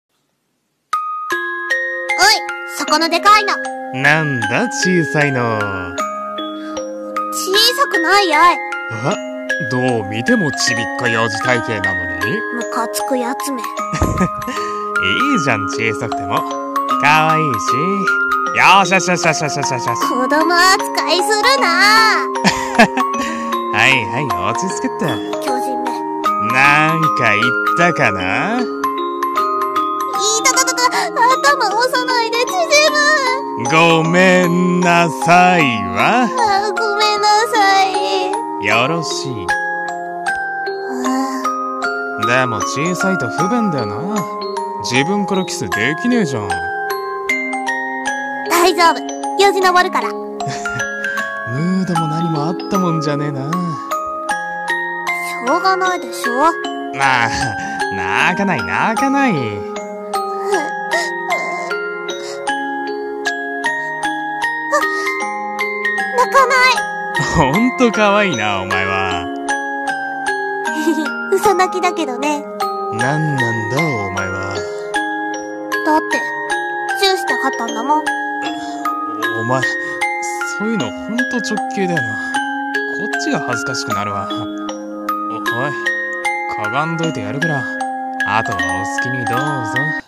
声劇】身長差カップル